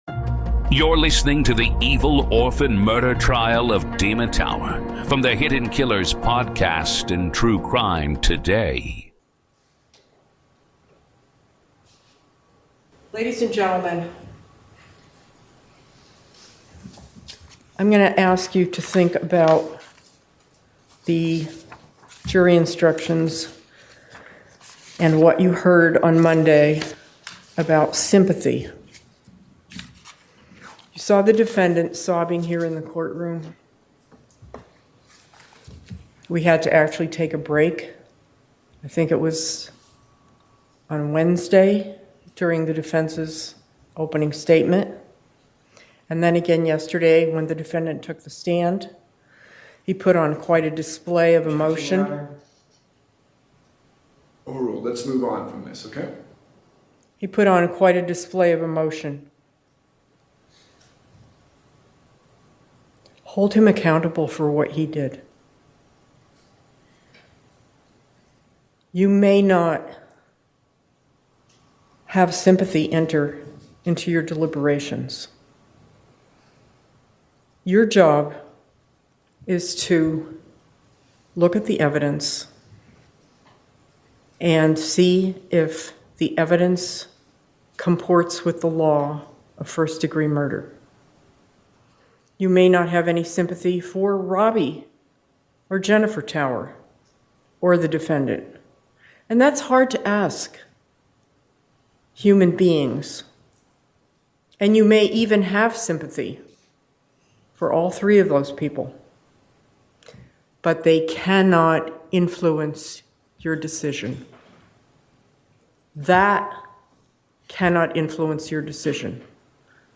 In this ongoing Hidden Killers courtroom-coverage series, we present the raw sounds of justice — no commentary, no edits — just the voices of attorneys, witnesses, and the judge as the case unfolds in real time.